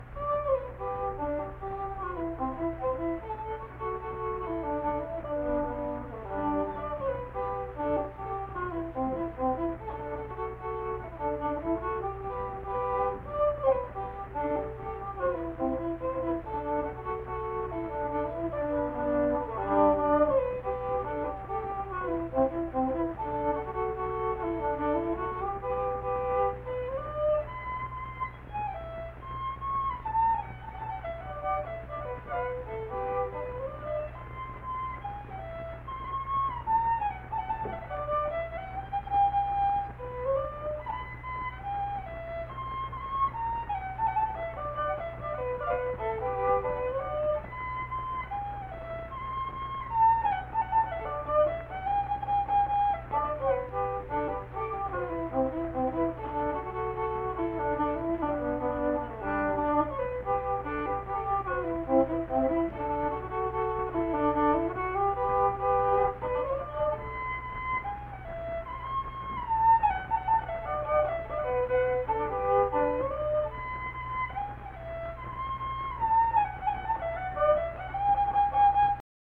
Unaccompanied fiddle music
Instrumental Music
Fiddle
Harrison County (W. Va.)